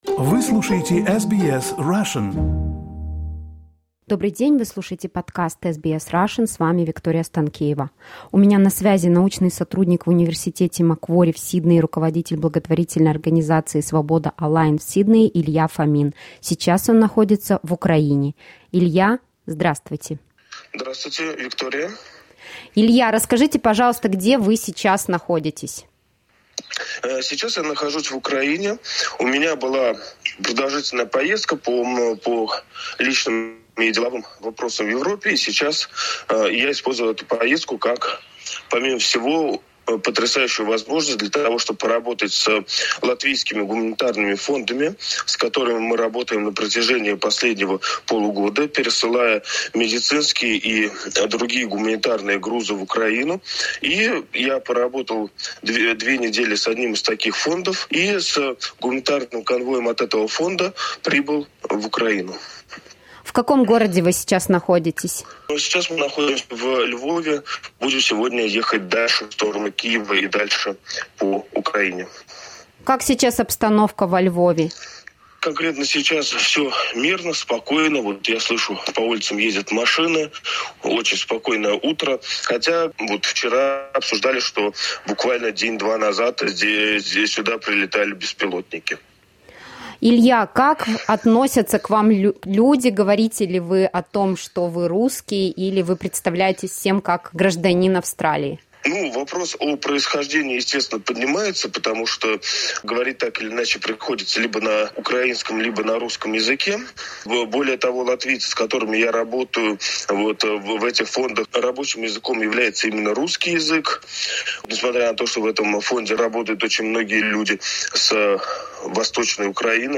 From Australia to Ukraine: interview